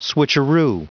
Prononciation du mot switcheroo en anglais (fichier audio)
Prononciation du mot : switcheroo